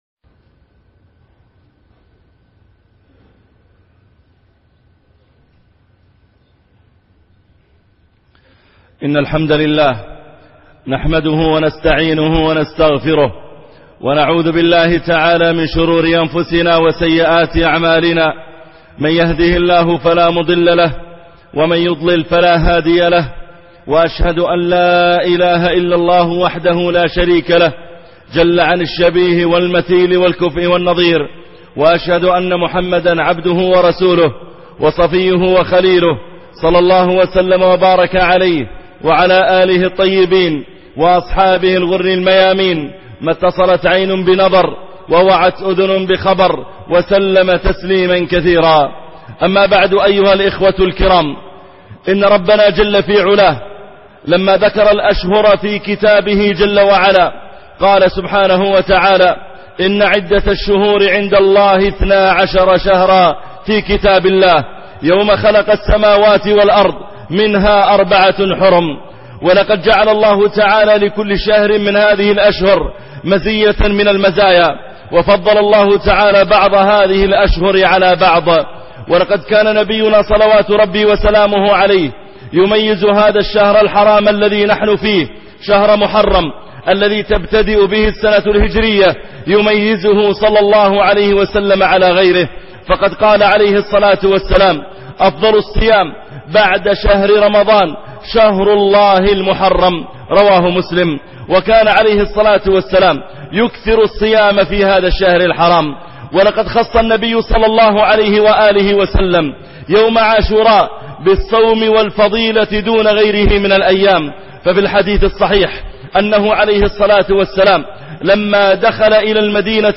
فضل محرم وعاشوراء- خطب الجمعة - الشيخ محمد العريفي